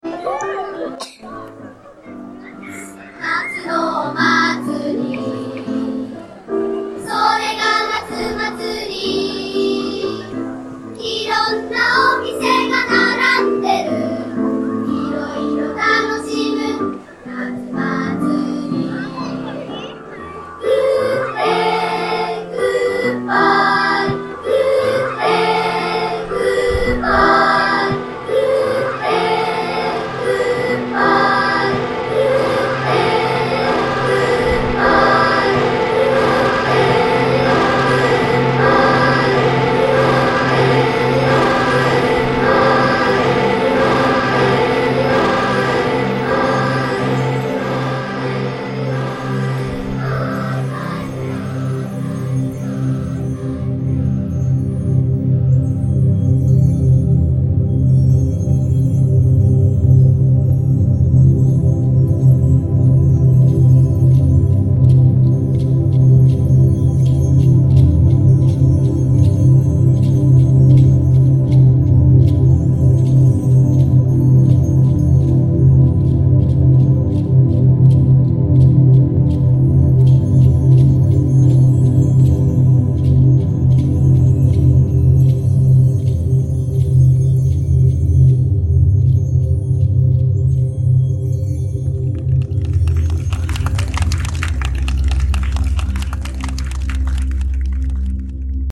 School at Soshigaya-Okua reimagined